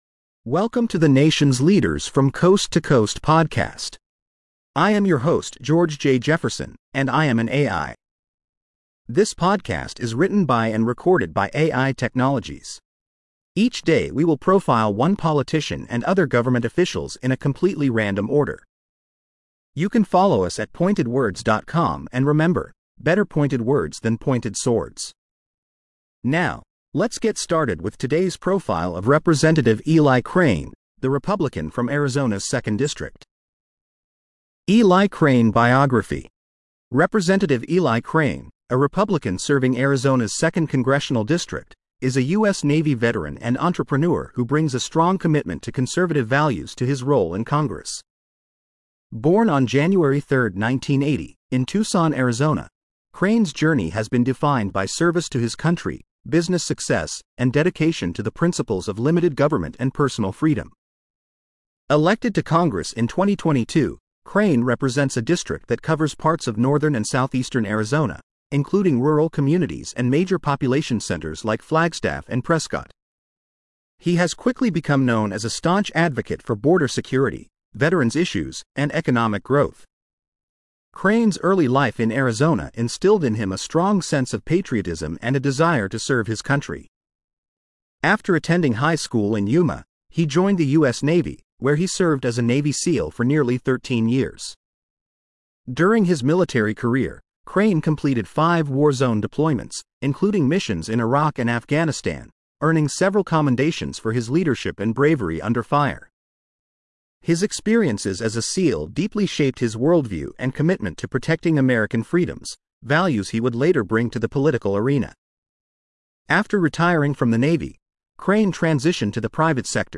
AI Profile of Rep. Eli Crane Republican Arizona 2nd District.